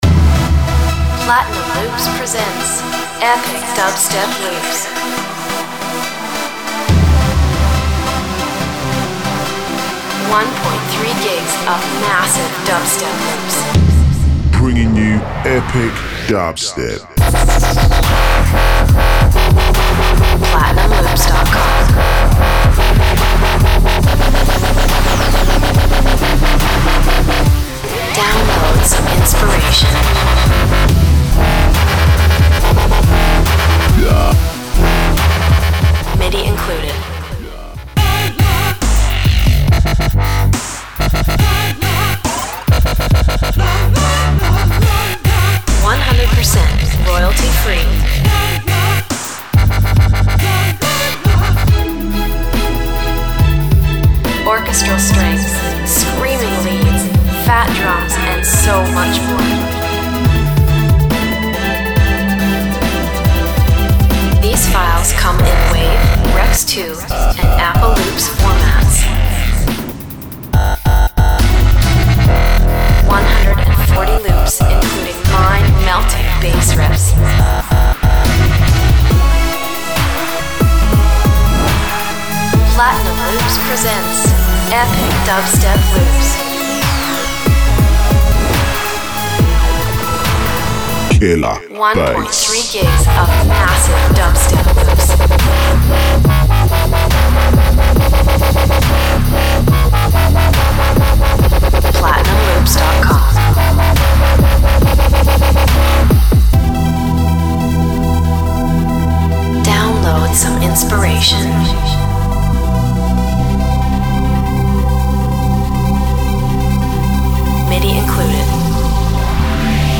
Download Loops and Samples 140 Bpm